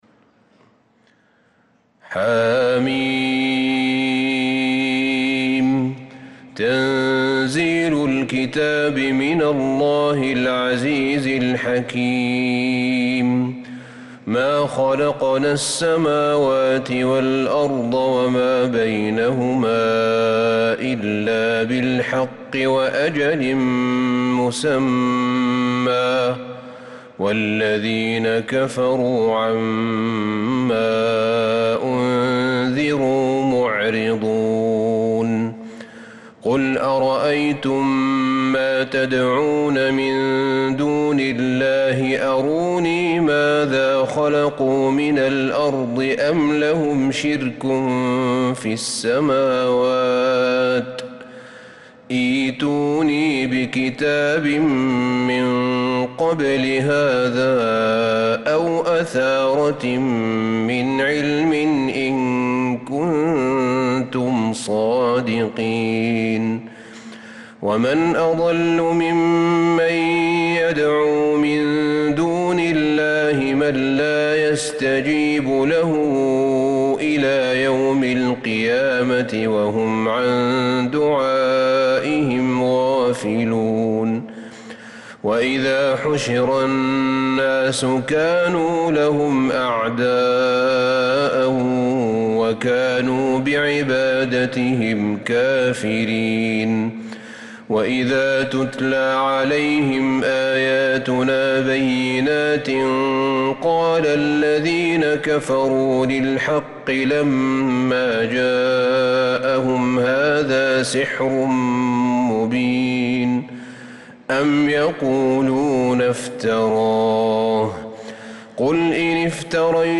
سورة الأحقاف كاملة للشيخ أحمد بن طالب مجمعة من فجريات شهر ربيع الأول 1446هـ > السور المكتملة للشيخ أحمد بن طالب من الحرم النبوي 2 🕌 > السور المكتملة 🕌 > المزيد - تلاوات الحرمين